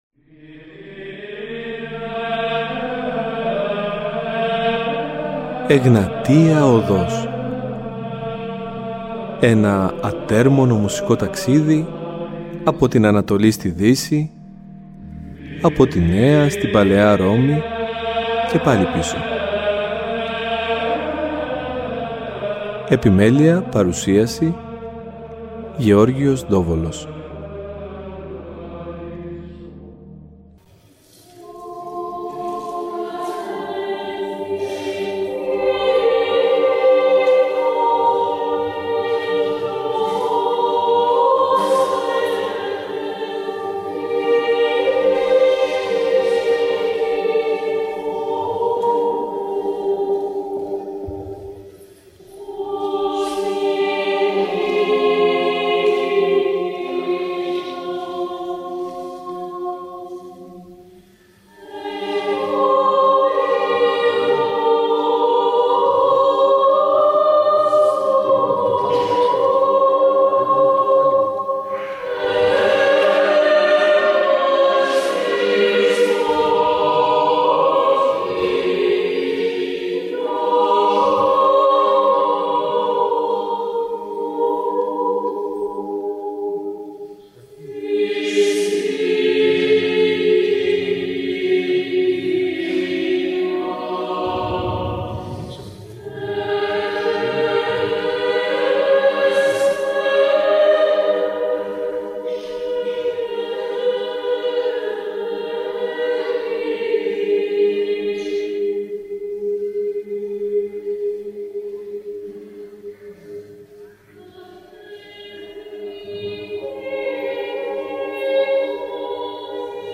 Μία χορωδία που διακρίθηκε μεταξύ άλλων στο χώρο της πολυφωνικής εκκλησιαστικής μουσικής. Τόσο η φρεσκάδα των παιδικών φωνών όσο και ο άρτιος ήχος αλλά κυρίως το μεράκι ήταν αυτά που την ξεχώρισαν. Κατάφερε να συνδυάσει το Βυζαντινό ύφος της Θεσσαλονίκης με την Ευρωπαϊκή τετραφωνία κάτι που εκ των πραγμάτων έχει μείνει ιστορικό.